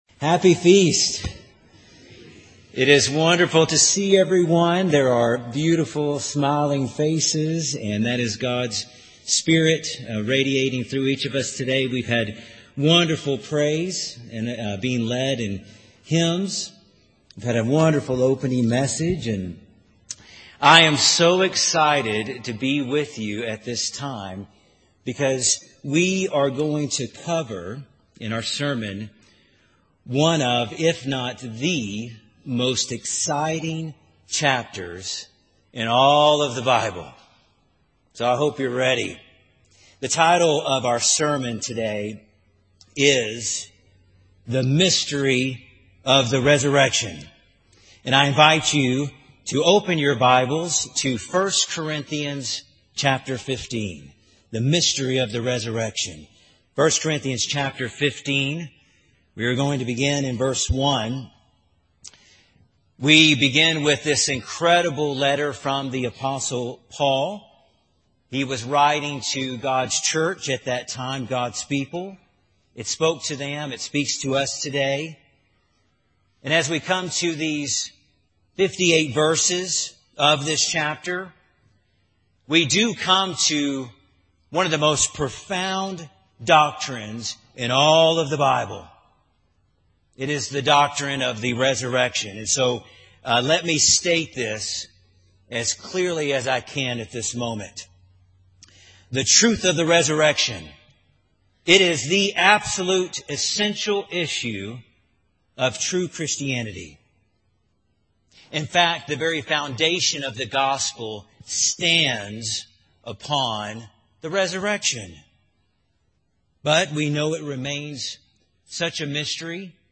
This sermon was given at the Estes Park, Colorado 2022 Feast site.